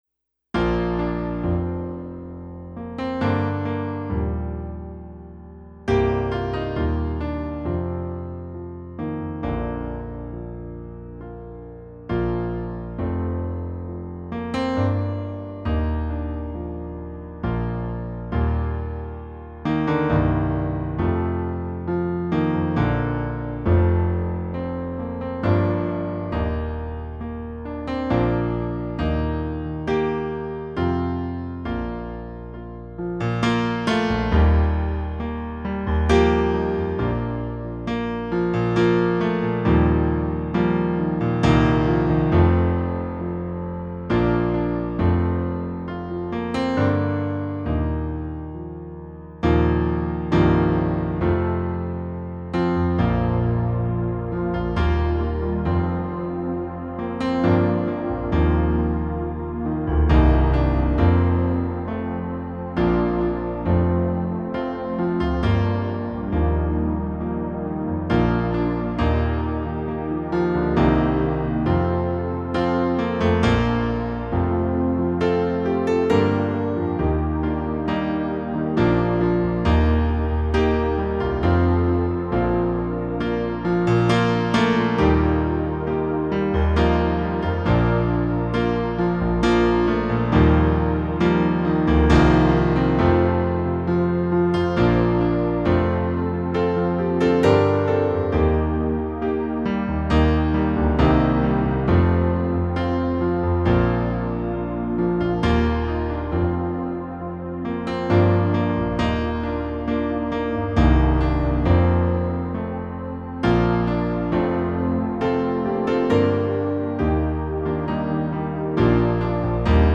worship song
(instrumental)